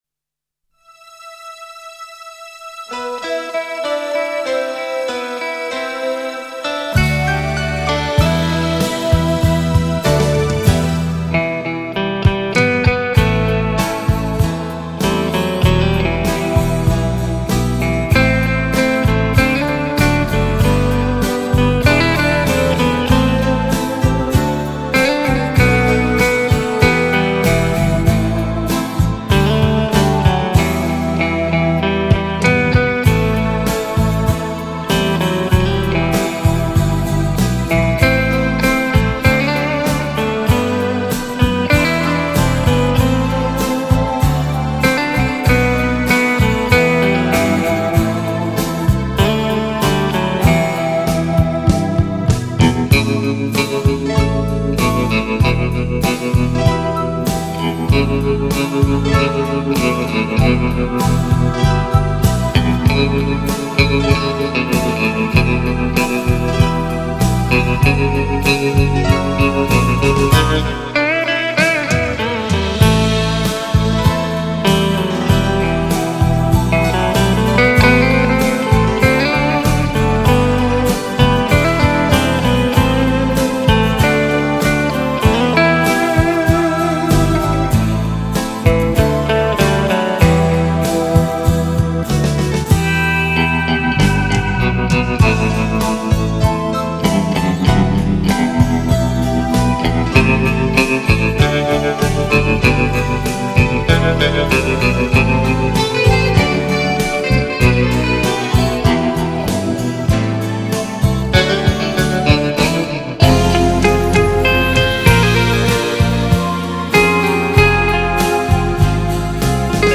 Спокойная мелодия без слов
красивая музыка без слов